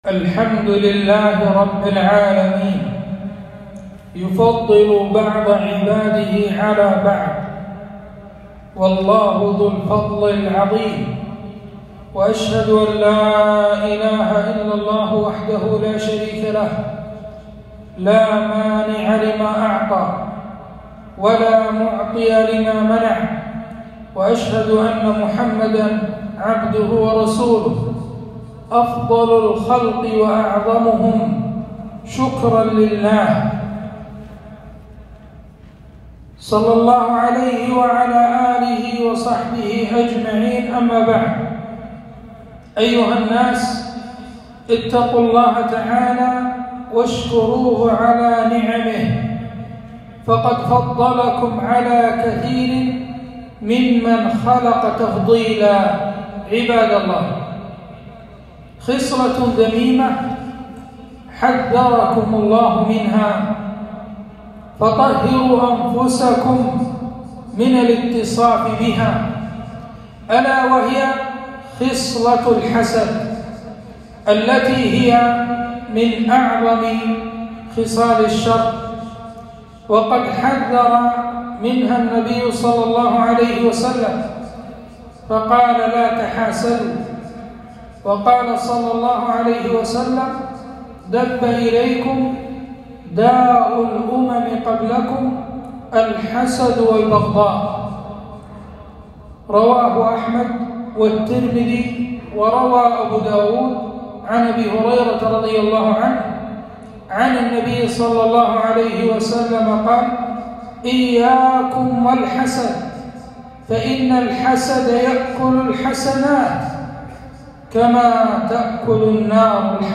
خطبة - طهر قلبك من الحسد